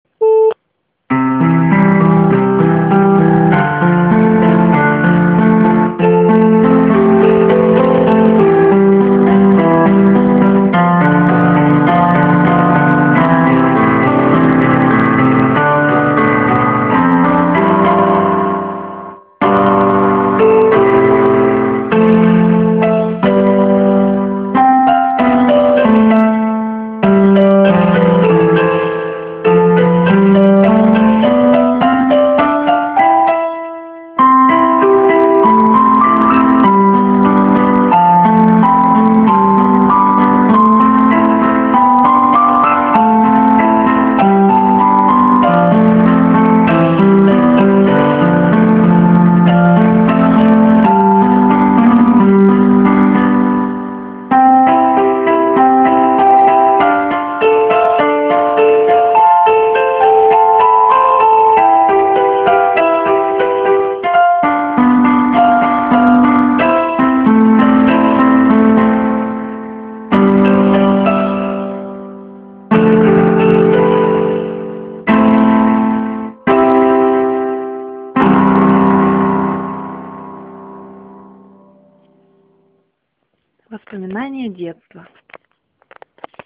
Авторская мелодия, написанная в 2008 году.
Легко воспринимается и захватывает с первого прослушивания - было пару замечательных мест, на которые просто отзывалось сердце (я даже сохранила вашу мелодию себе на диске,- правда, жаль, что запись непрофессиональная и слышатся фоновые шумы, но это не портит саму мелодию).